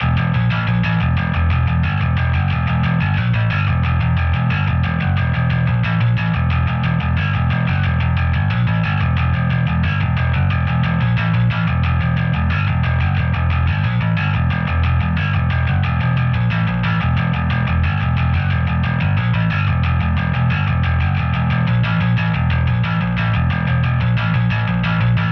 Ezbass DI in den Murder One, bisschen EQ und Compression. Eine Spur, ohne Splitting oder sonstigen Schnick-Schnack.